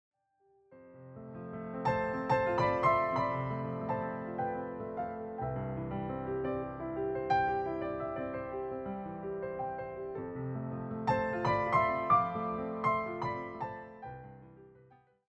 all performed as solo piano arrangements.